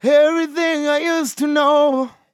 Vocal Sample
Categories: Vocals Tags: dry, english, everything, I, know, LOFI VIBES, LYRICS, male, sample, To, used
MAN-LYRICS-FILLS-120bpm-Am-23.wav